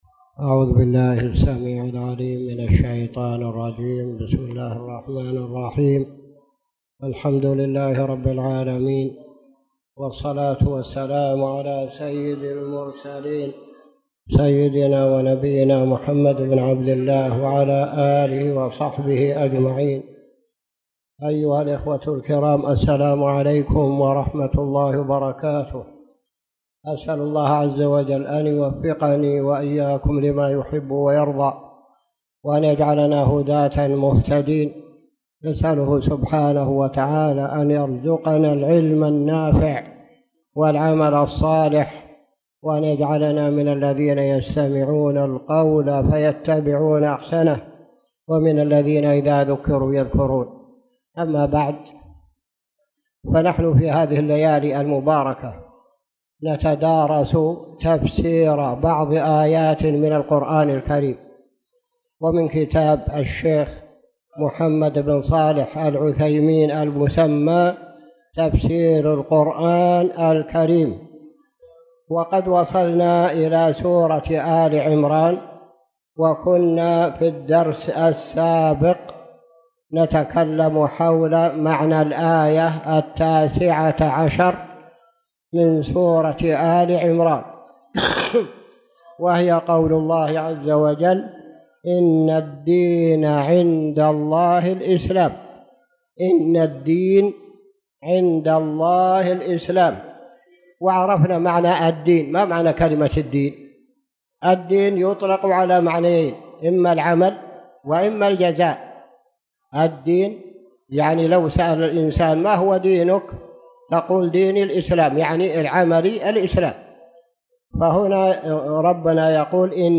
تاريخ النشر ٢٣ ربيع الثاني ١٤٣٨ هـ المكان: المسجد الحرام الشيخ